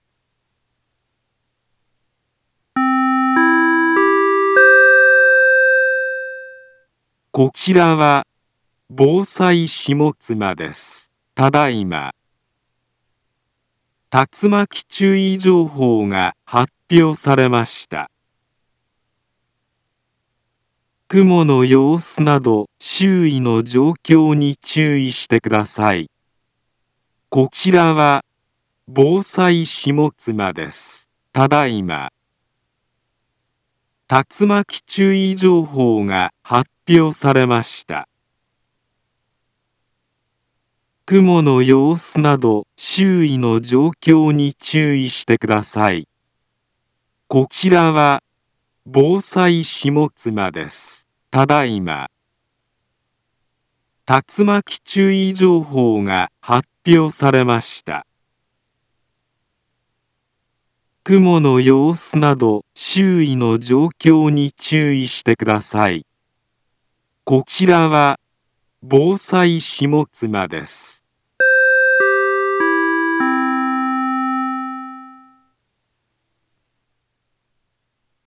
Back Home Ｊアラート情報 音声放送 再生 災害情報 カテゴリ：J-ALERT 登録日時：2023-09-08 14:09:51 インフォメーション：茨城県南部は、竜巻などの激しい突風が発生しやすい気象状況になっています。